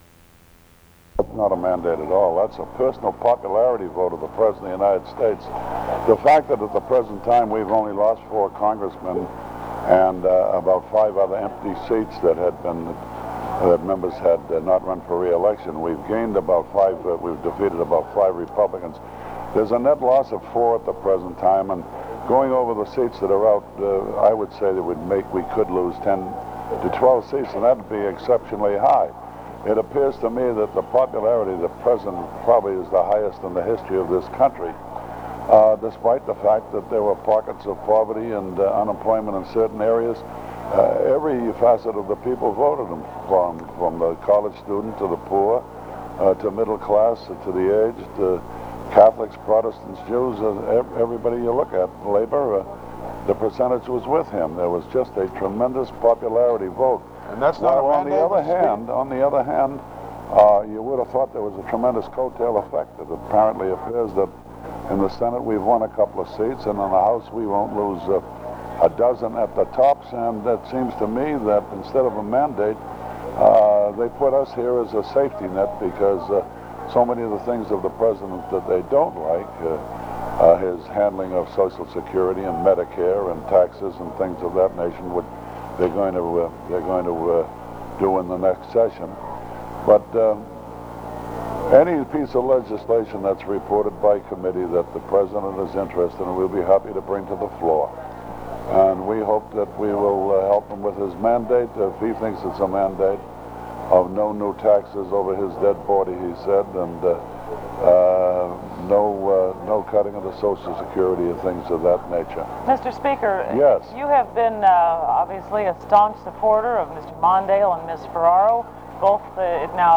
U.S. Speaker of the House Tip O'Neill speaks about Ronald Reagan's 1984 election victory and Democratic victories in Congress. O'Neill also speaks about the future of Democratic Party leadership and how the party plans to work with President Reagan on issues such as the defecit.